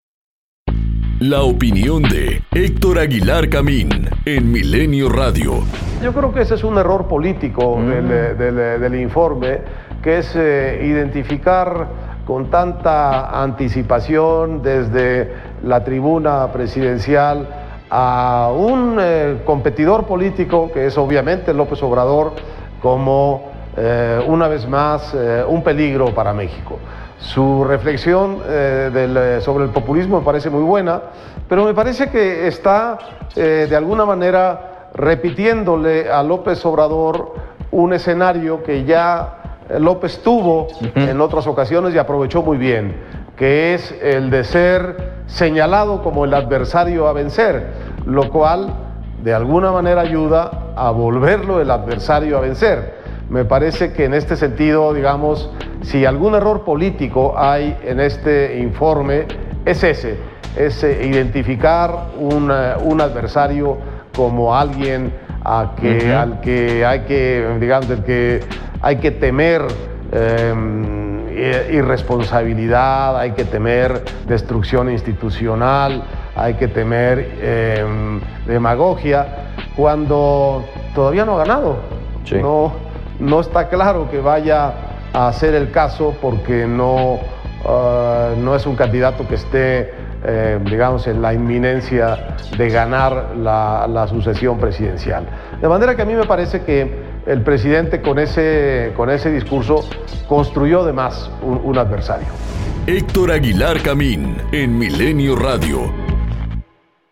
COMENTARIO EDITORIAL 070915